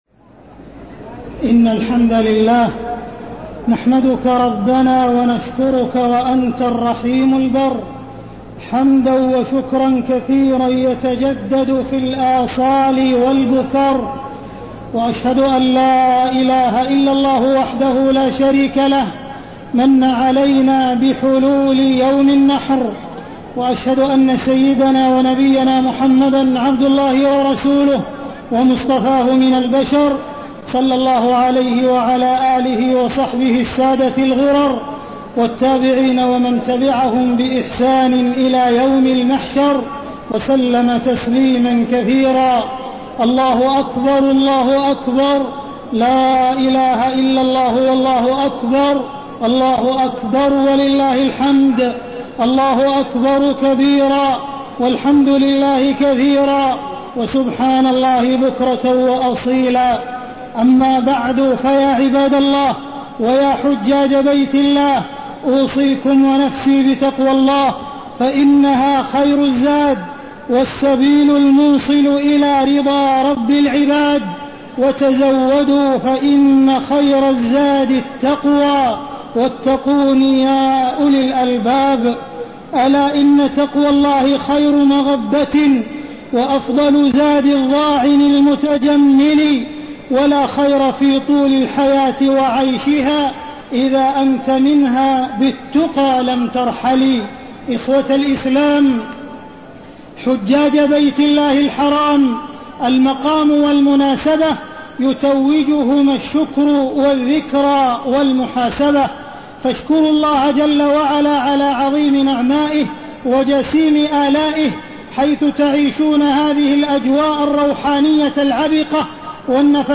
تاريخ النشر ١٠ ذو الحجة ١٤٣٠ هـ المكان: المسجد الحرام الشيخ: معالي الشيخ أ.د. عبدالرحمن بن عبدالعزيز السديس معالي الشيخ أ.د. عبدالرحمن بن عبدالعزيز السديس نعمة الله على الحجيج